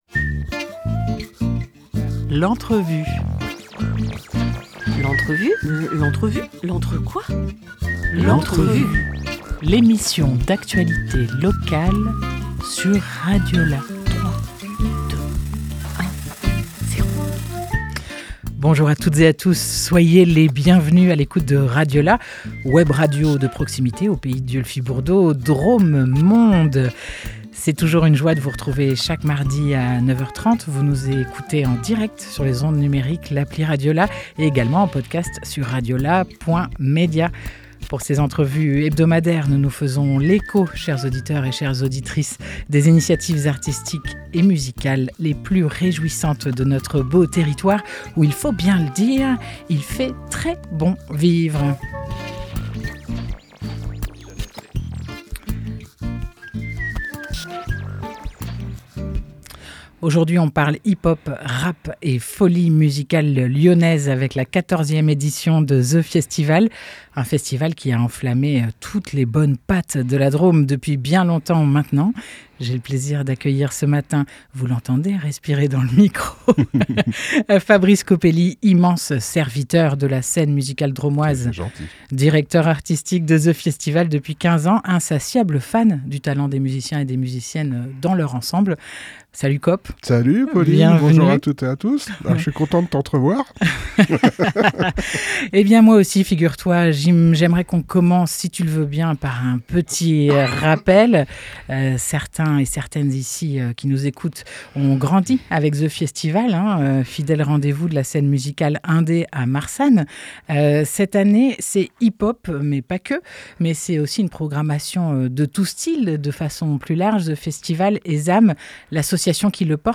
20 octobre 2025 15:18 | Interview